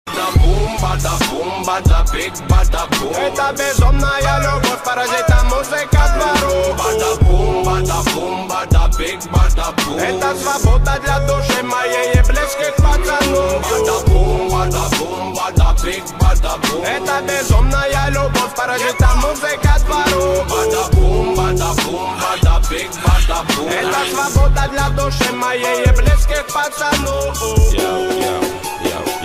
Russian hip-hop duo